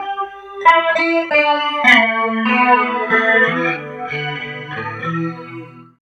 29 GUIT 1 -L.wav